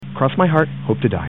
Category: Radio   Right: Personal
Tags: Radio The Curse Of Dracula Play Horror Bram Stoker